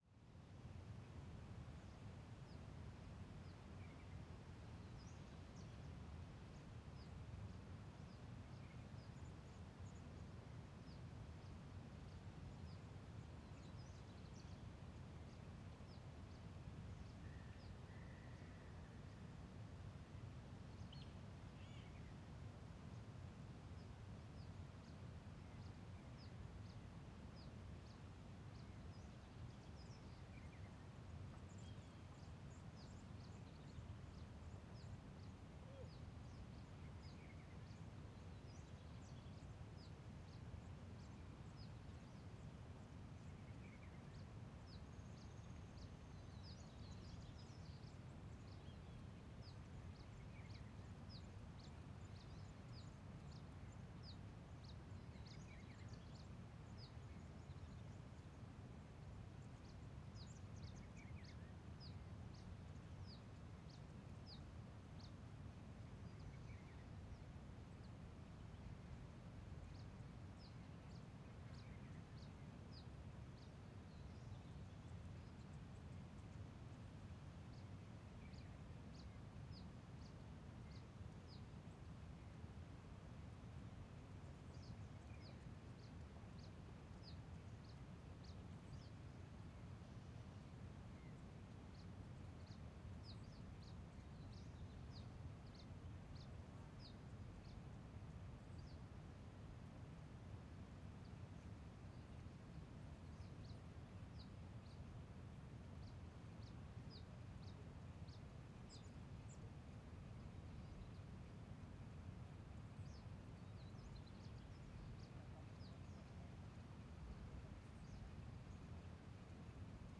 Um biblioteca digital com 2000 sons originais do Cerrado, 100% GRATUITOS para seus projetos audiovisuais.
Ambiencia Mirante proximo a cachoeira com diversos passaros ao fundo
Mata aberta , Pássaros , Vale da Lua , Vento
Stereo
CSC-05-009-GV - Ambiencia Mirante proximo a cachoeira com diversos passaros ao fundo.wav